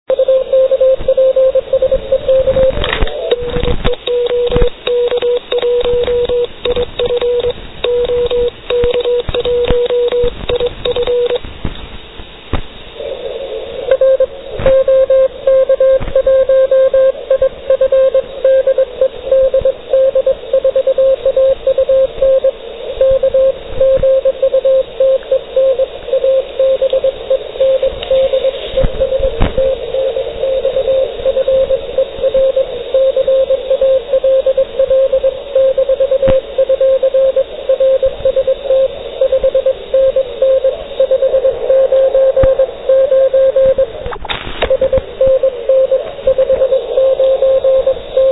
Všimněte si prolézání silných BC stanic z kmitočtu 3995 KHz.
QSO SE SELII ( zvuk 98 KB ) ( vysílám na ruční klíč HI )